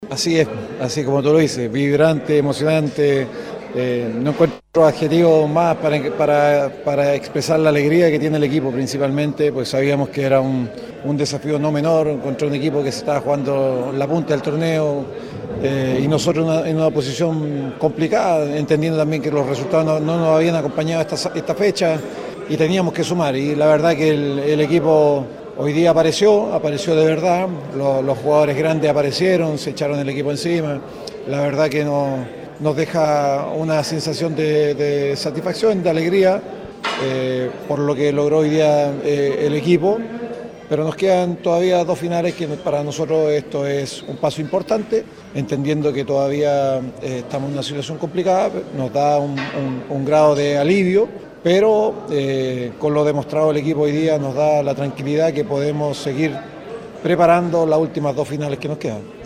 Así lo comentó en diálogo con Gigante Deportivo